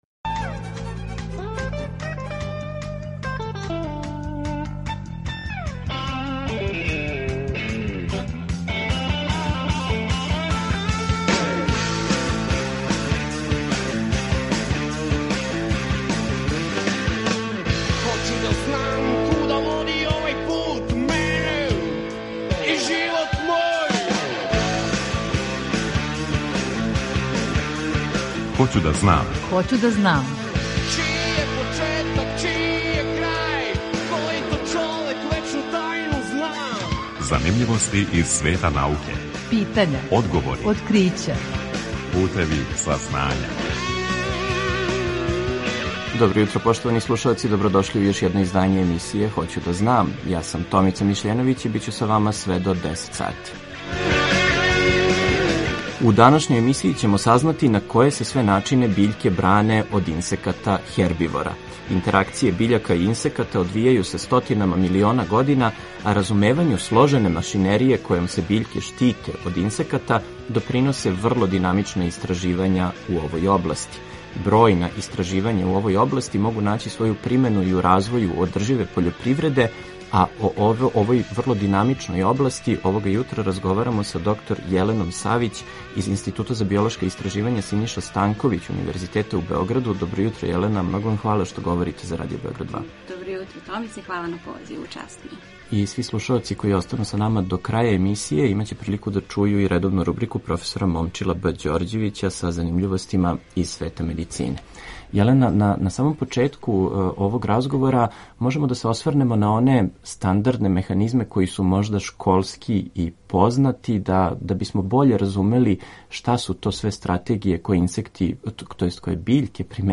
Разговор је први пут емитован 19.05.2022.